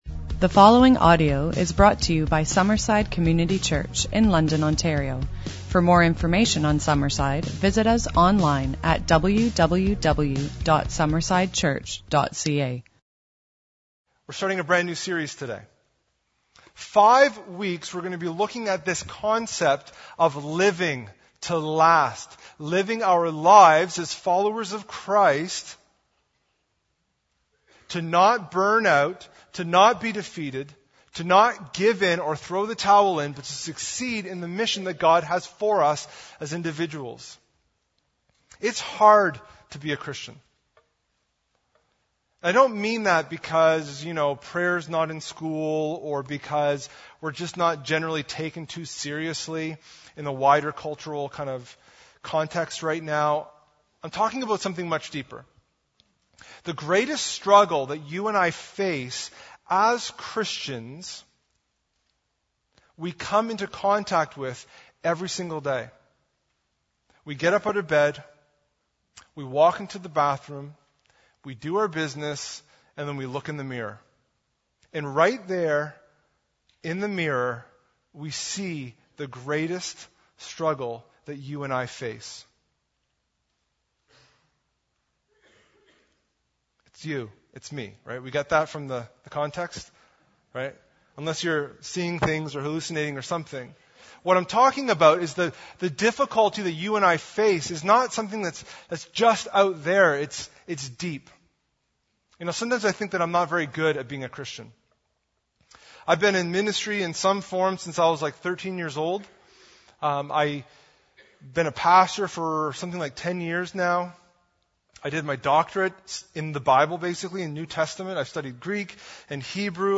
New Sermon Series ~ Living to Last – Living to Last Isn’t Easy